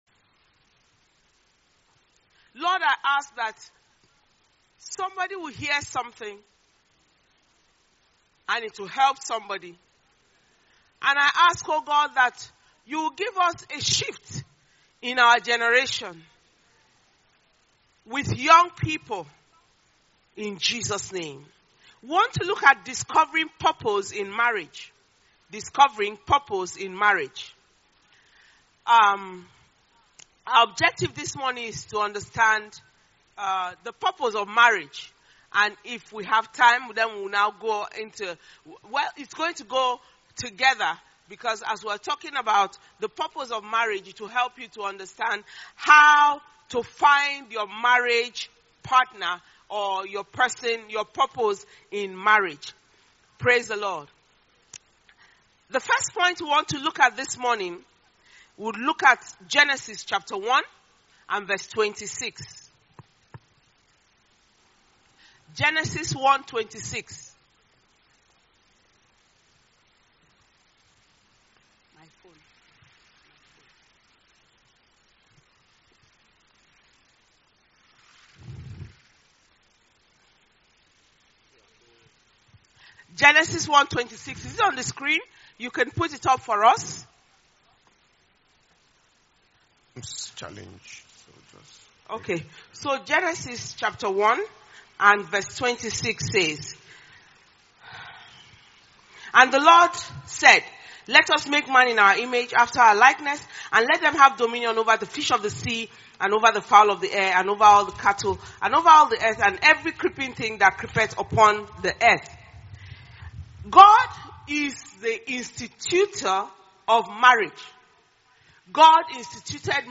Dunamis Youth 2021 Global Convention Messages